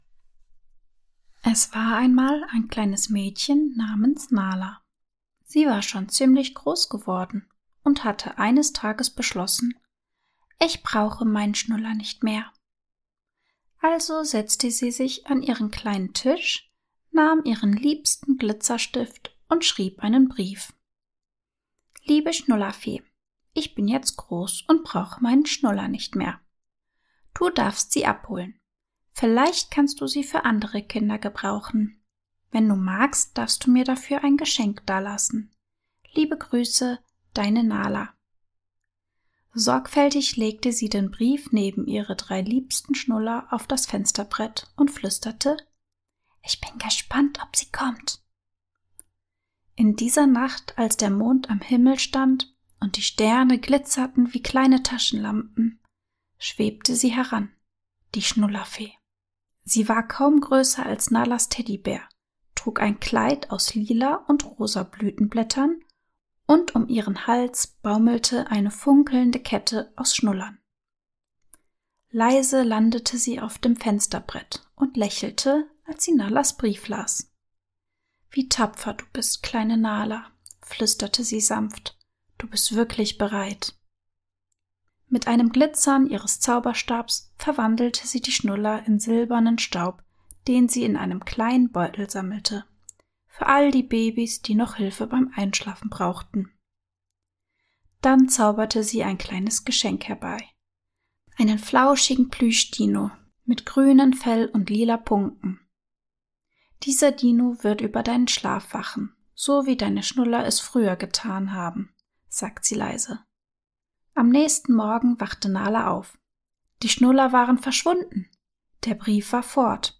In dieser liebevoll erzählten Gute-Nacht-Geschichte begleiten wir